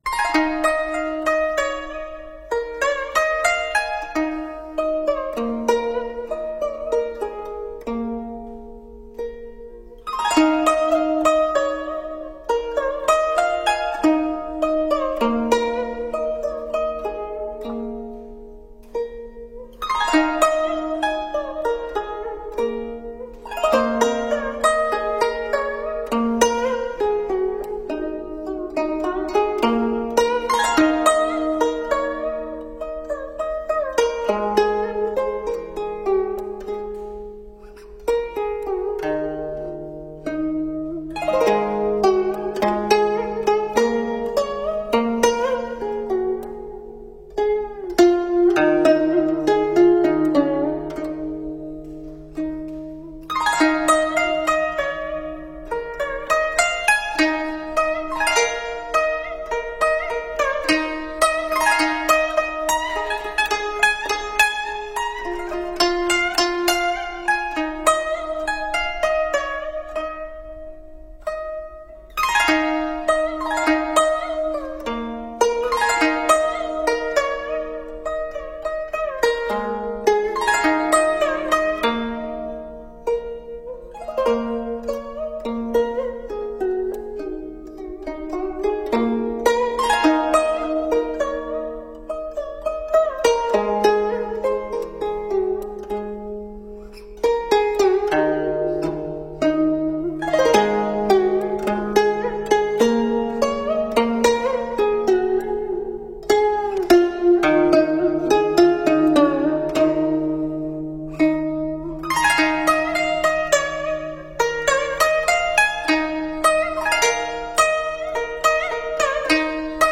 出水莲--古筝